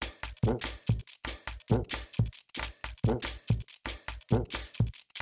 Bunde tol loop.mp3
Folclor Colombiano, Región Andina, instrumentos musicales, instrumentos de percusión, ritmos musicales